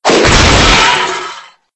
ENC_cogfall_apart_2.ogg